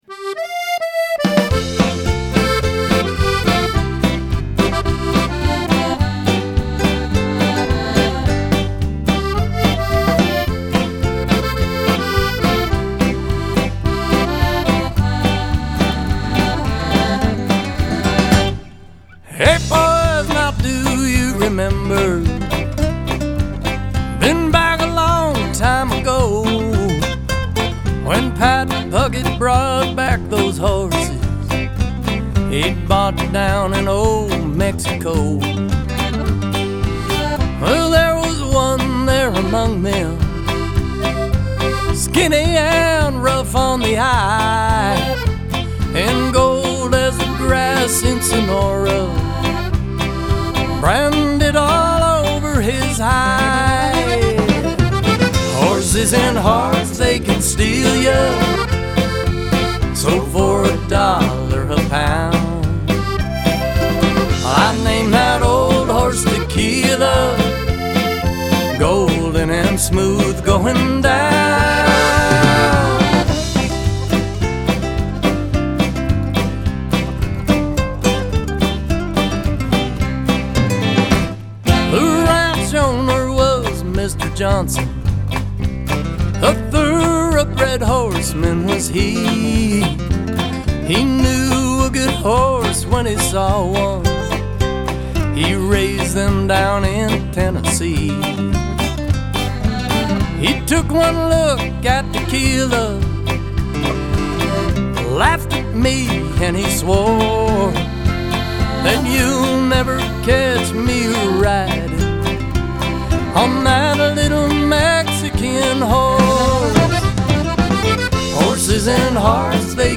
Cowboy Poet | Performer